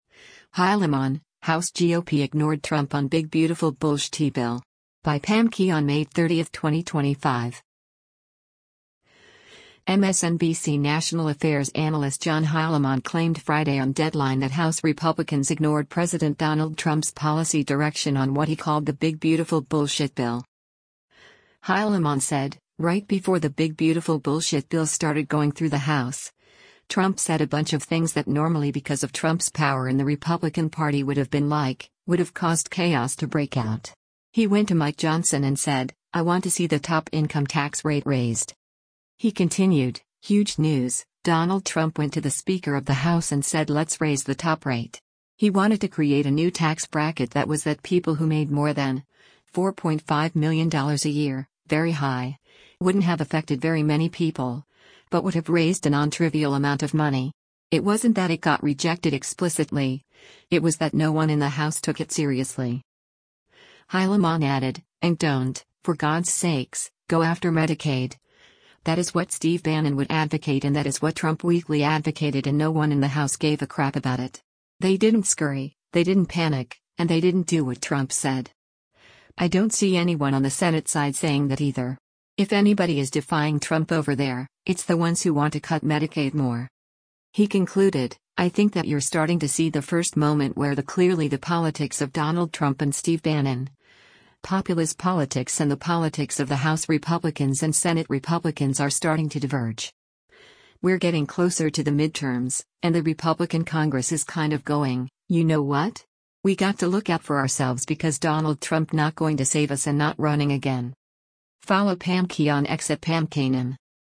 MSNBC national affairs analyst John Heilemann claimed Friday on “Deadline” that House Republicans ignored President Donald Trump’s policy direction on what he called the “big beautiful bullshit bill.”